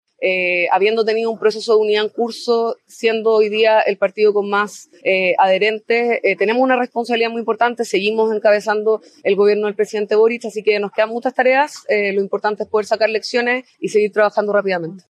De manera previa, eso sí, la timonel del Frente Amplio, Constanza Martínez, afirmó que el resultado de la elección no se traduce en una “derrota estructural ni estratégica del proyecto político” del partido.